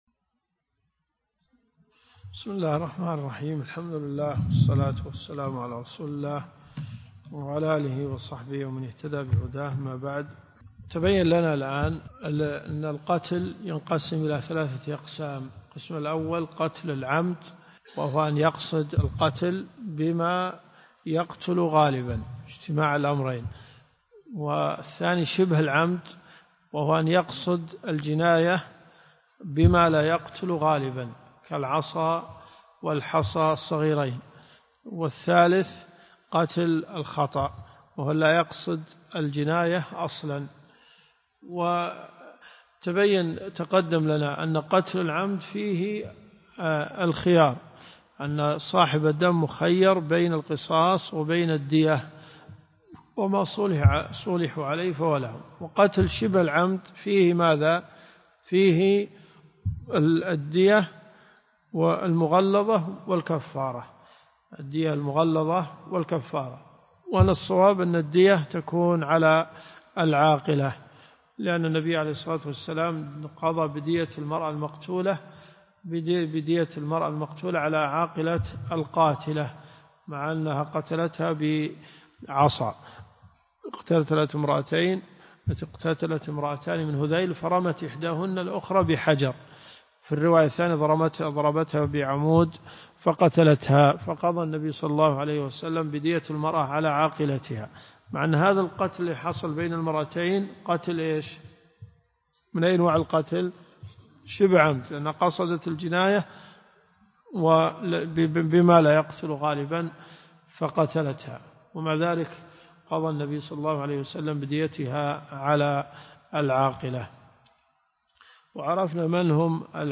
الدروس الشرعية
المدينة المنورة . جامع البلوي